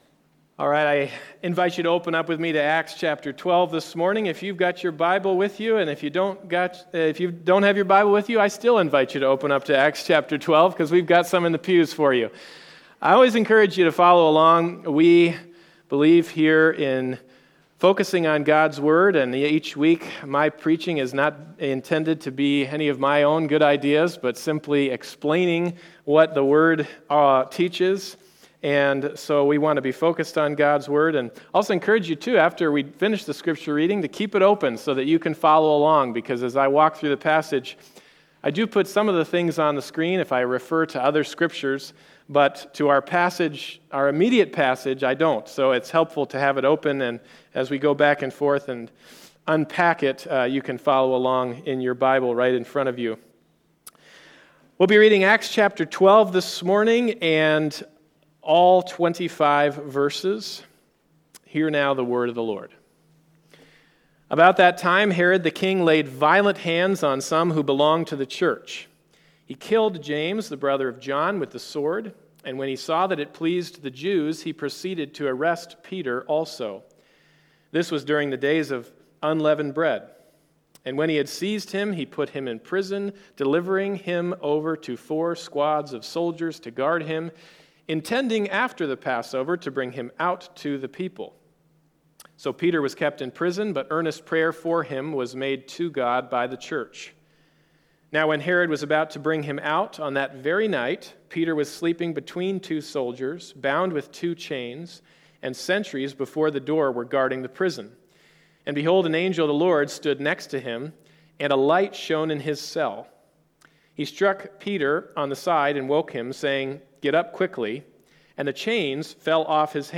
Acts 12:1-25 Service Type: Sunday Morning Service « Are You a Christian?